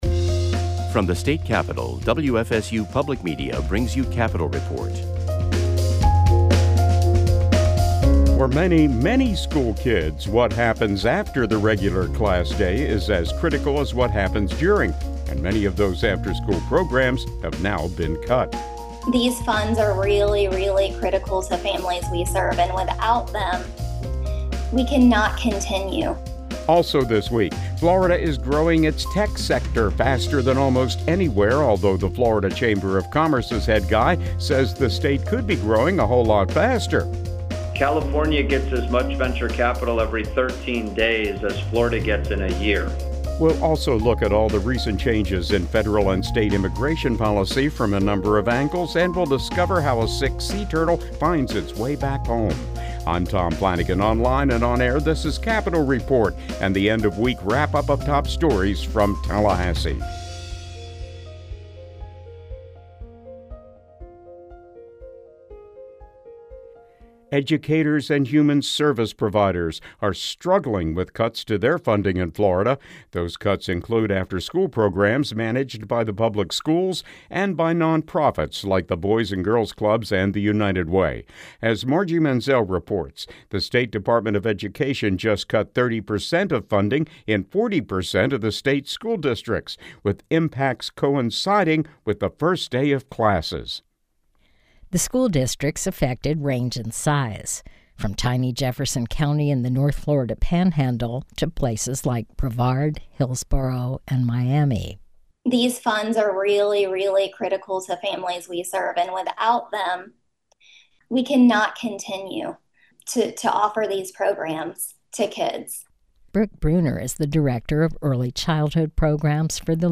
WFSU Public Media reporters, as well as reporters from public radio stations across the state, bring you timely news and information from around Florida. Whether it's legislative maneuvers between sessions, the economy, environmental issues, tourism, business, or the arts, Capital Report provides information on issues that affect the lives of everyday Floridians.